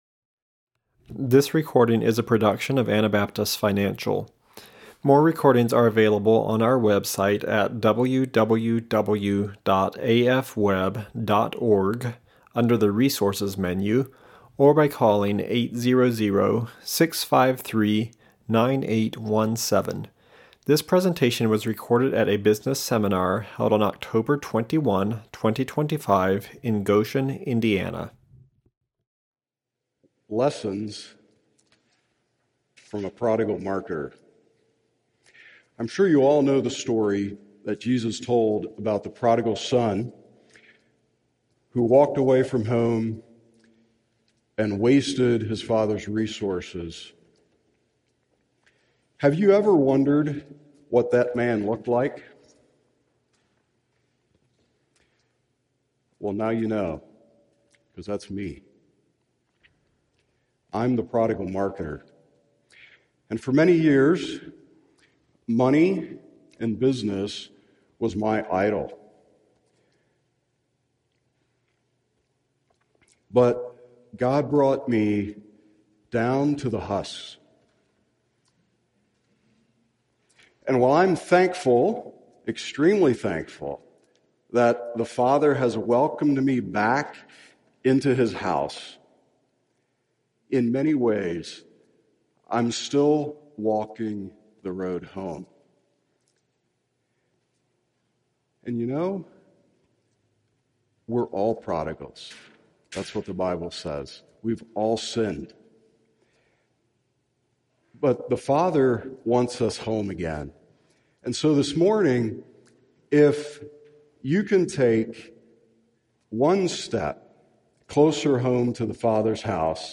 Indiana Business Seminar 2025